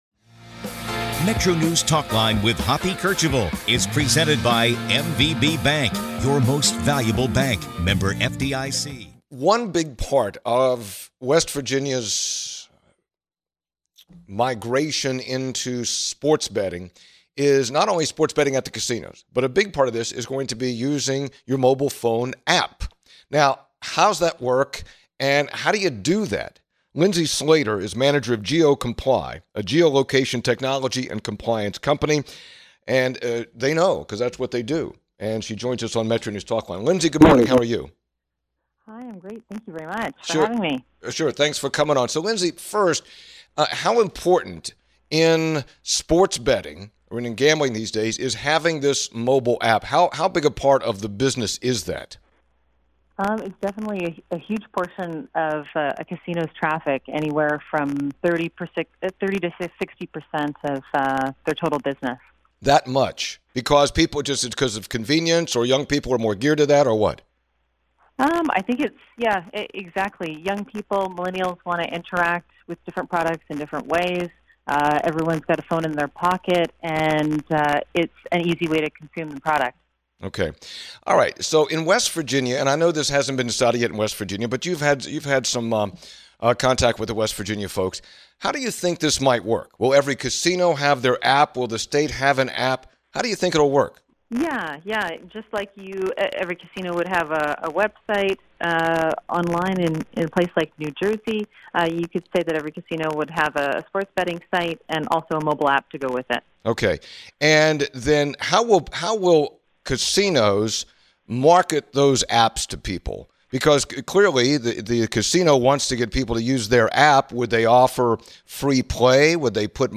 West Virginia radio